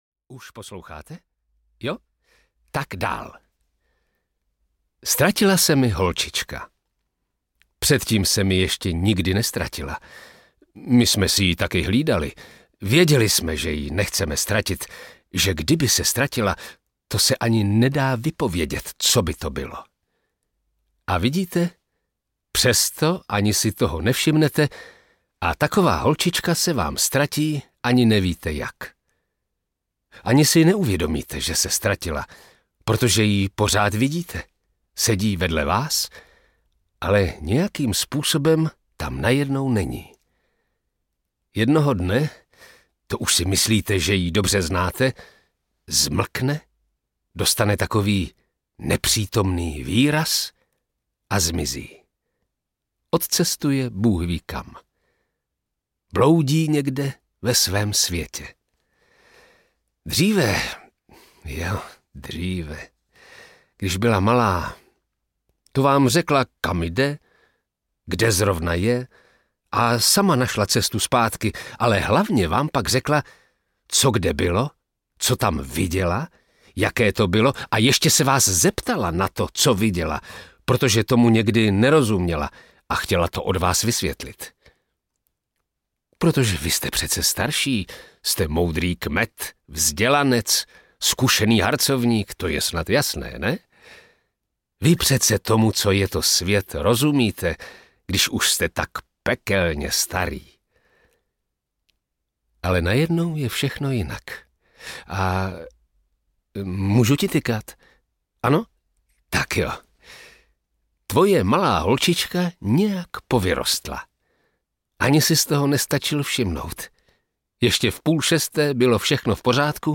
Opožděné romány audiokniha
Ukázka z knihy